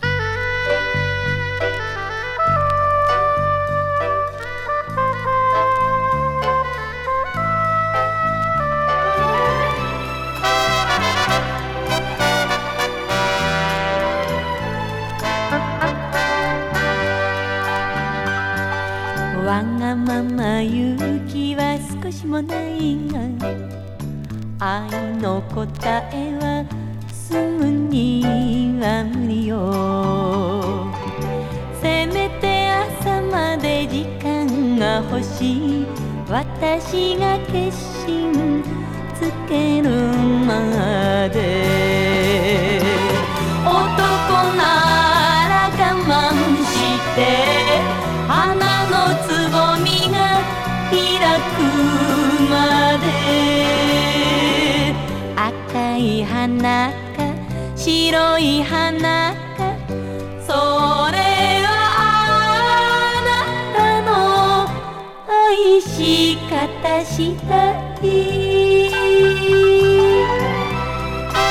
ゴージャズ歌謡。